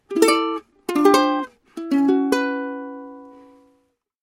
Готовимся играть на укулеле